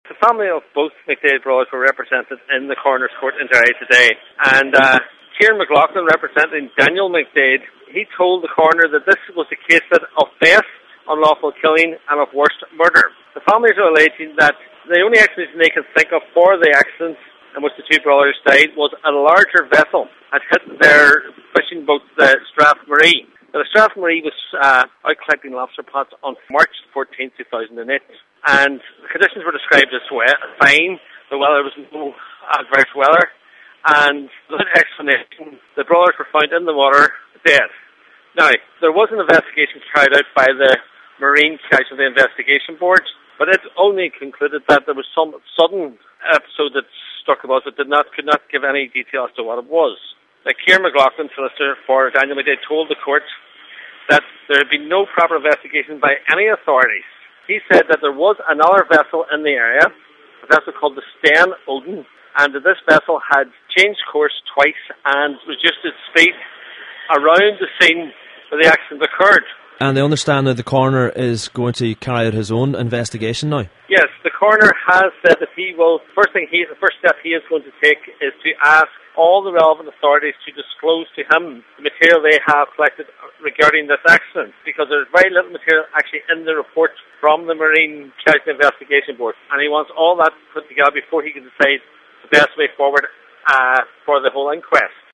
Journalist